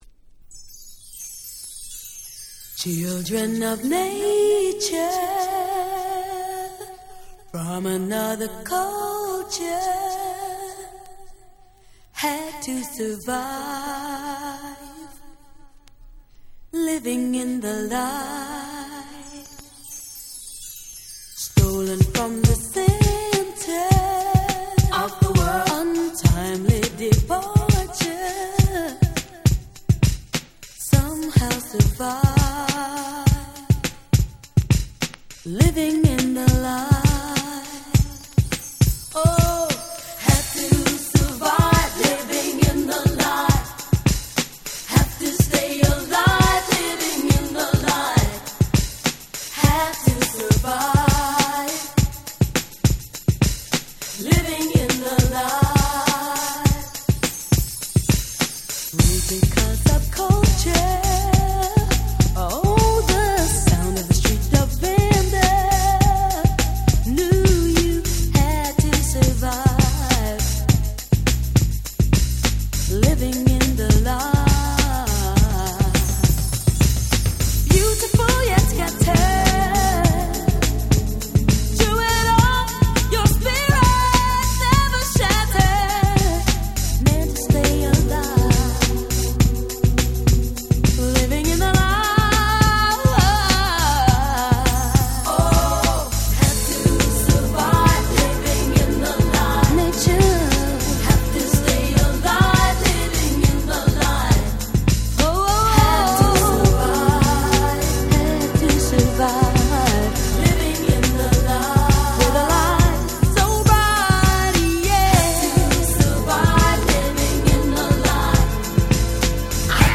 90' UK R&B Classic !!
グラウンドビート Ground Beat